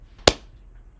clap-detection
clap-04.wav